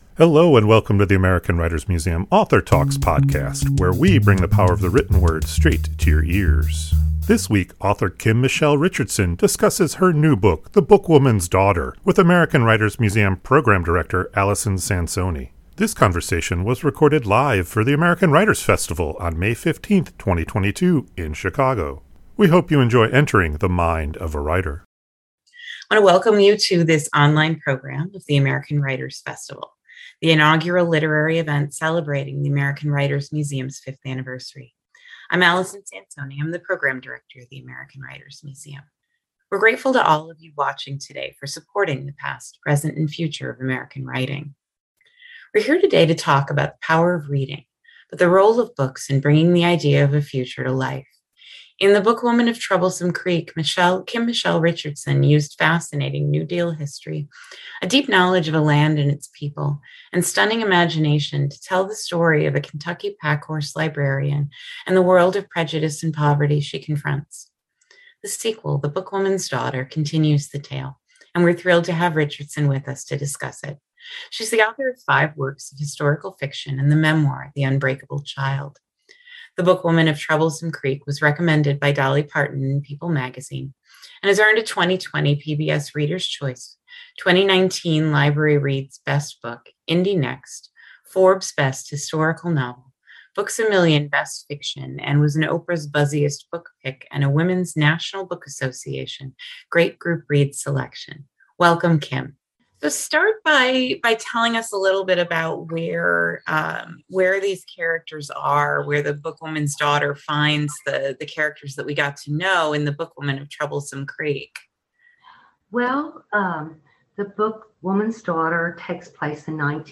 This conversation was recorded live for the American Writers Festival on May 15, 2022 in Chicago.